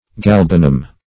Galban \Gal"ban\, Galbanum \Gal"ba*num\, n. [L. galbanum, Gr. ?,